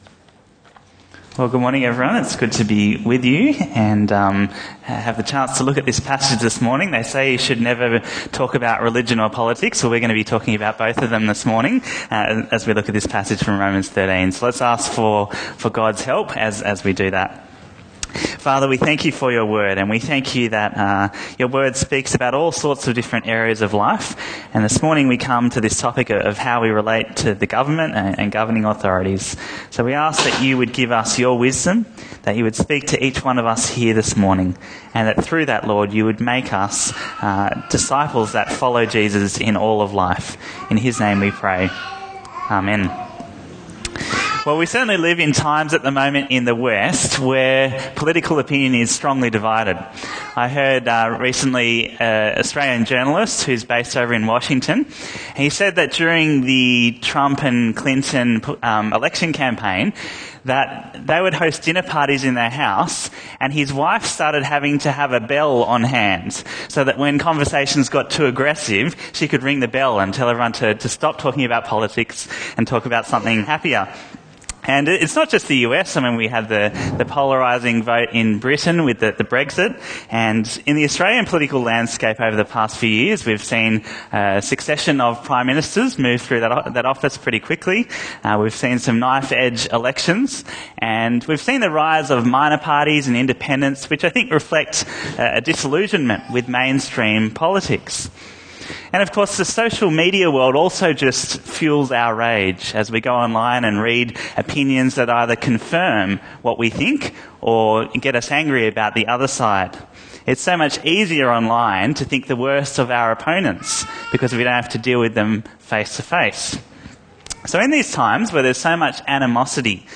Bible Talks Bible Reading: Romans 13:1-7